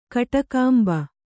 ♪ kaṭakāmba